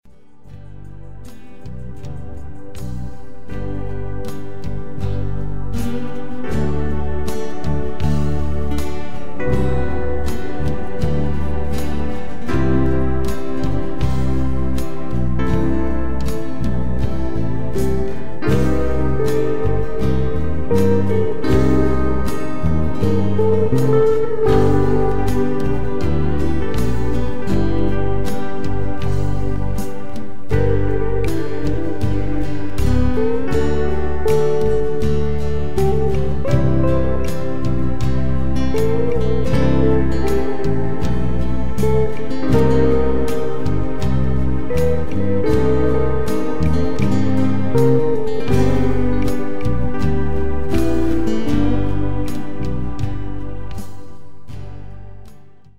Sie sind zum träumen und entspannen gedacht.